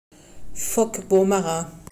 uttale)